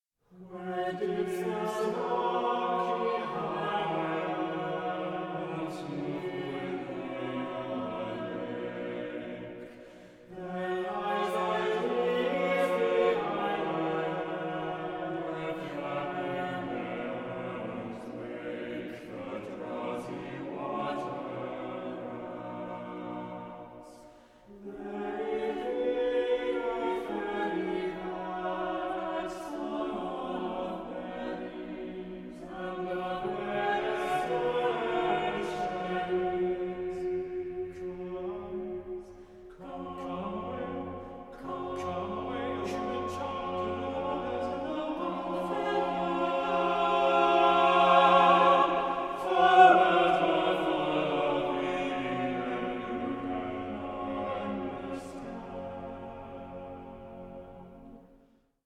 tenor
baritone